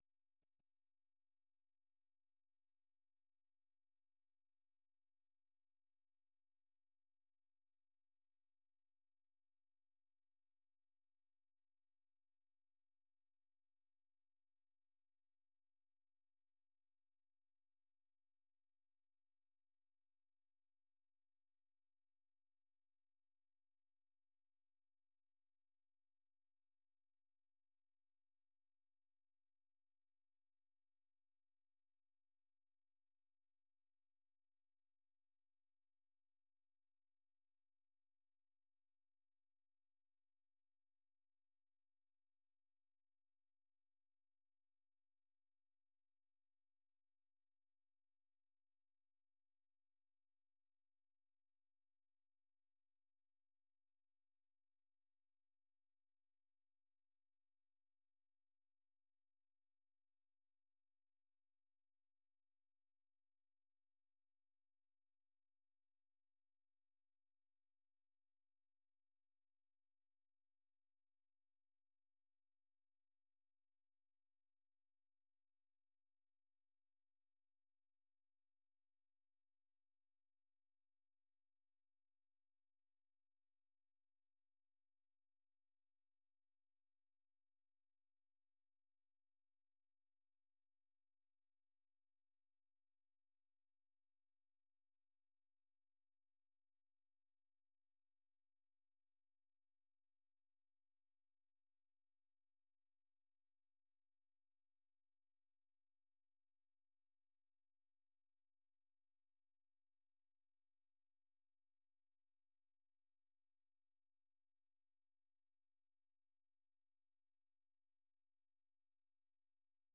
Oferece noticias do dia, informação, analises, desporto, artes, entretenimento, saúde, questões em debate em África. Às sextas em especial um convidado explora vários ângulos de um tema.